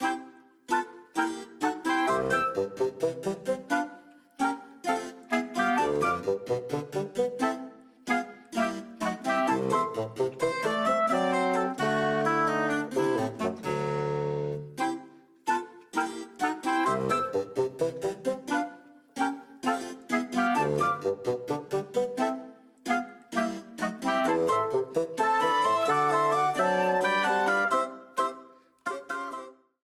Ripped from the game
clipped to 30 seconds and applied fade-out
Fair use music sample